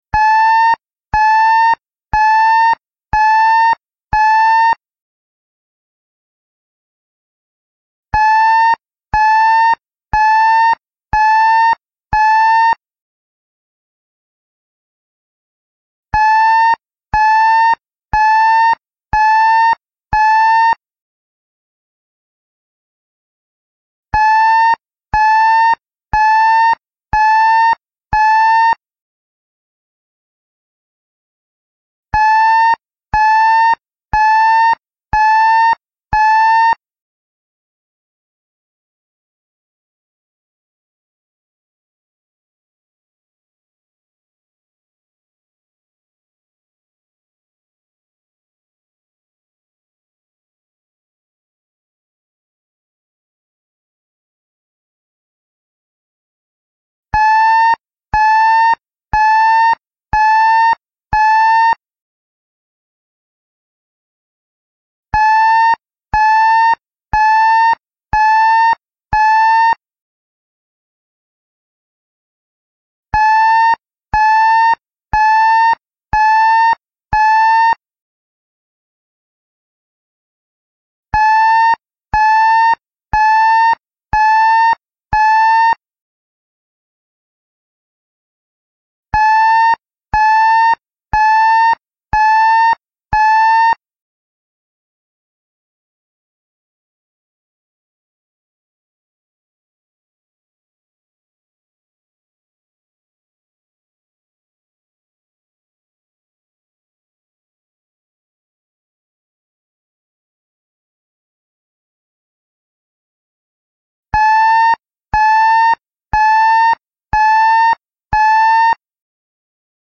This system installs a water level gauge at the confluence of Katabira River and Ima-Igawa rivers (near Ima-Igawa Kawaguchi Bridge) and informs residents of the dangers of floods using a two-step siren sound.
When the water level exceeds the sounding water level (160 cm) of the first stage siren, the pat light at the Kawaguchi Bridge Water Level Station lights and rotates, and the siren is sounded at the speaker station.
Listening of the first stage siren (Music file (MP3): 1,094KB)